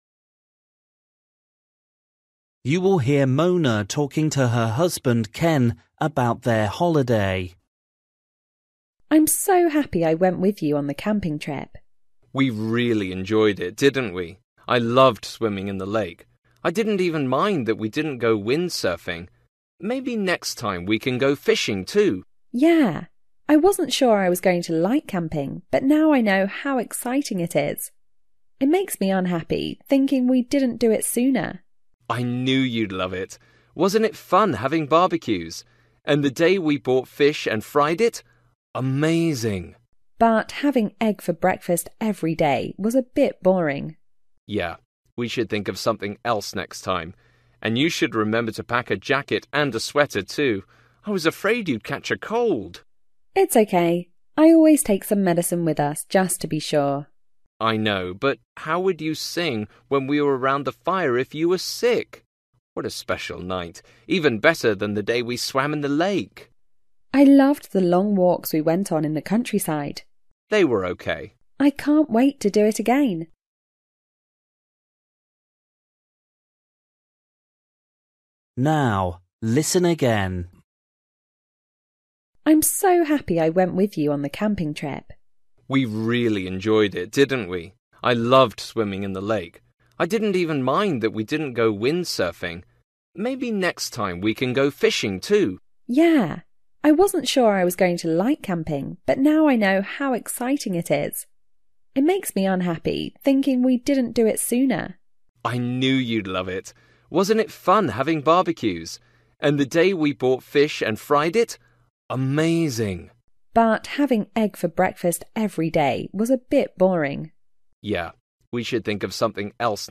Bài tập trắc nghiệm luyện nghe tiếng Anh trình độ sơ trung cấp – Nghe một cuộc trò chuyện dài phần 24 - Thực Hành Tiếng Anh
You will hear Mona talking to her husband Ken about their holiday.